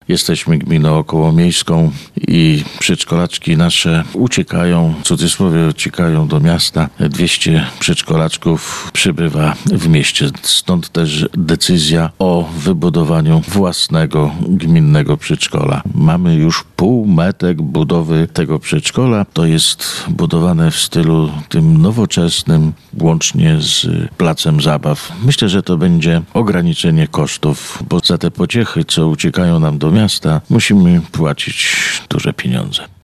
– Unijne dofinansowanie to 800 tysięcy złotych – mówi wójt gminy Biała Podlaska, Wiesław Panasiuk.